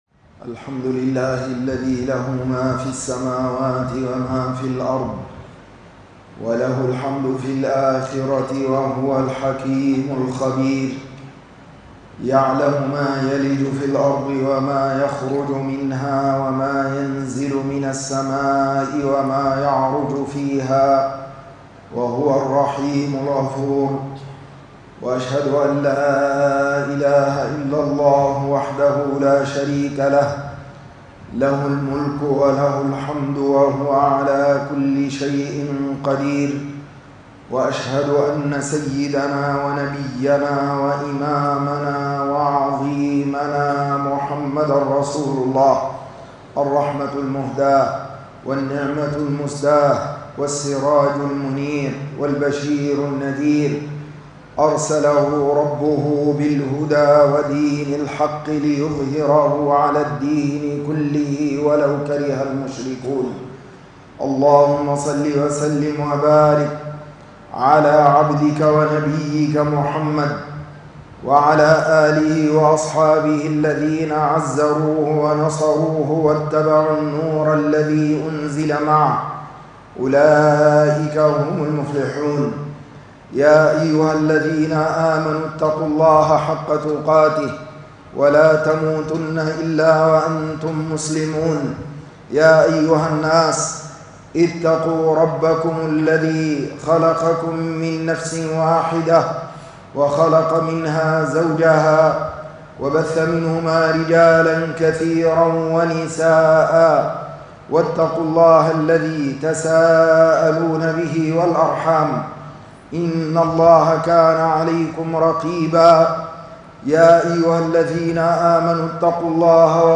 حرب من الله - خطبة الجمعة